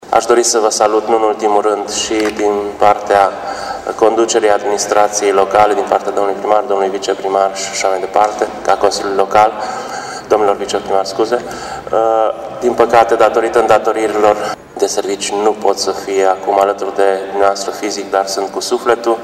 Manifestările dedicate romilor de pretutindeni au fost organizate miercuri,  în sala de Consiliu Local a Primăriei Timișoara și ar fi trebuit să înceapă cu intonarea imnului internațional al rromilor.